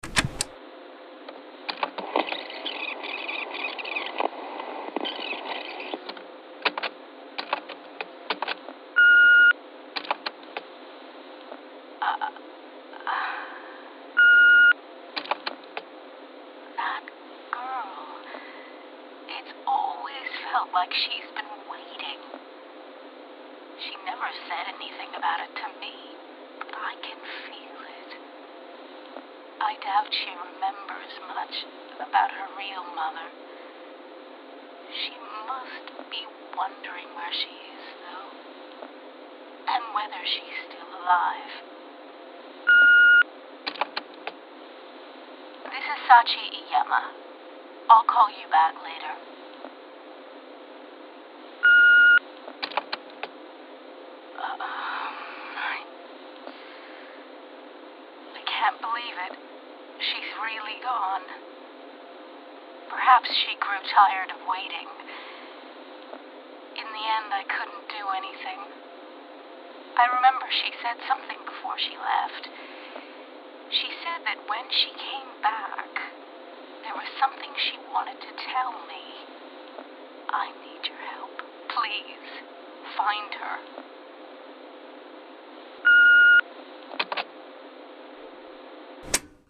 It is a voice recording of a phone call from a client.
Additional Description A cassette tape from an answering machine.